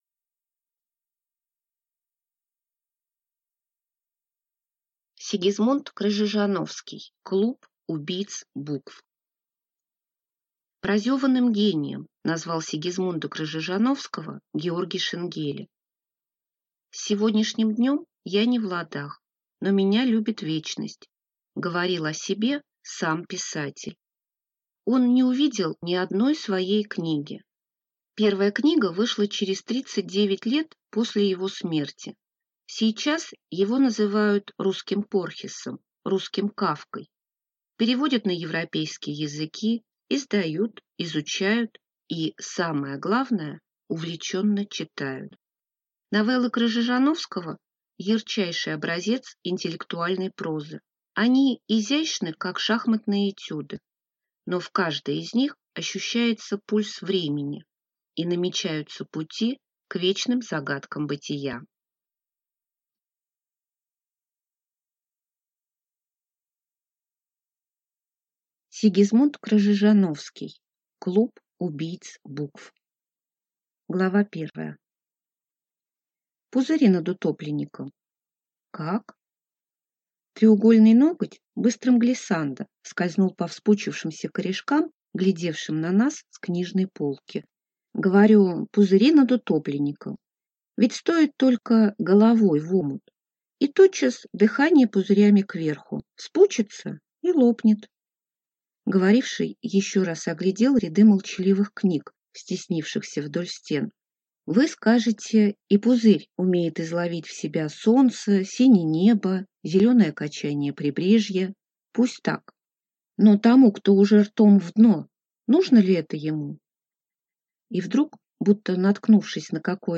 Аудиокнига Клуб убийц Букв | Библиотека аудиокниг
Прослушать и бесплатно скачать фрагмент аудиокниги